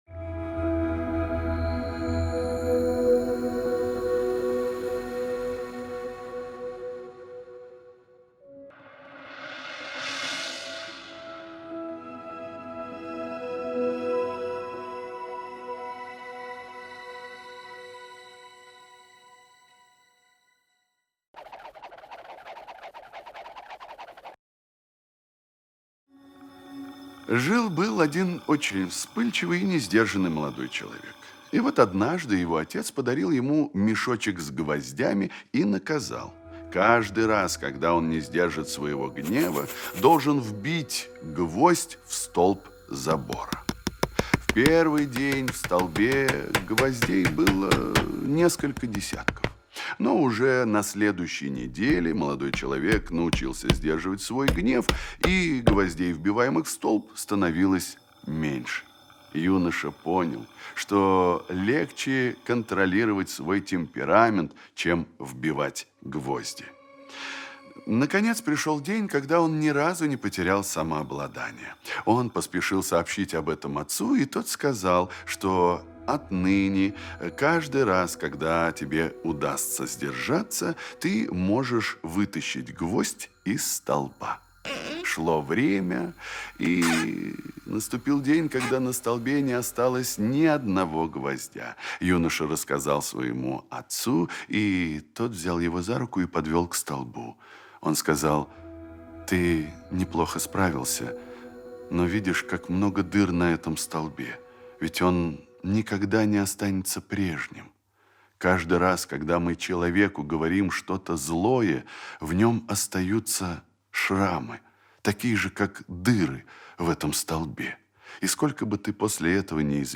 Шрамы на сердце - аудио притча- слушать онлайн
Текст читает Дюжев Д.